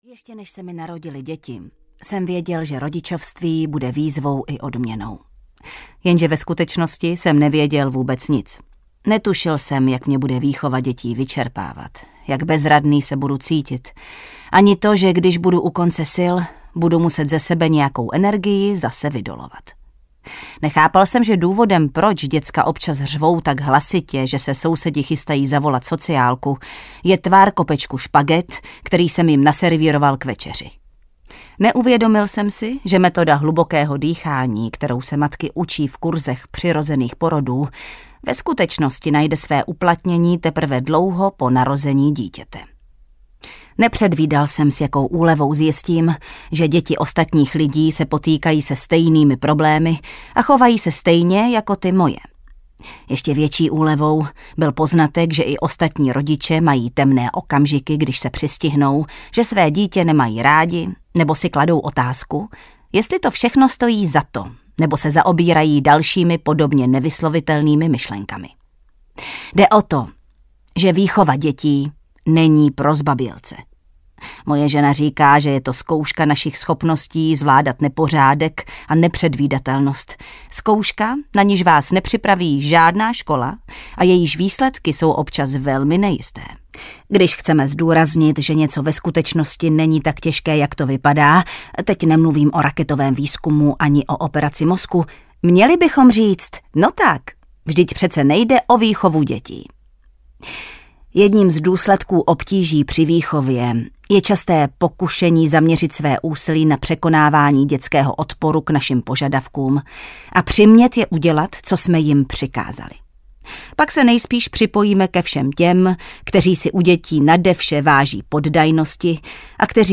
Bezpodmínečné rodičovství audiokniha
Ukázka z knihy
• InterpretJana Stryková